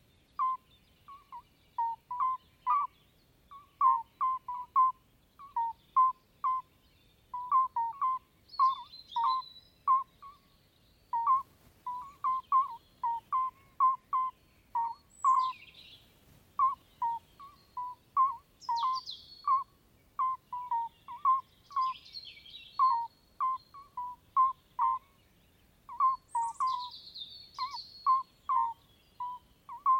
Au clic de la souris d’ordinateur ou du doigt sur écran tactile, le logo emmétra un « tut » enregistré dans une serve du Livradois-Forez.
* Rappelons qu’un Tut est, en langue vernaculaire, un crapaud accoucheur ou alyte qui ravit nos soirées de son plus doux flûtis.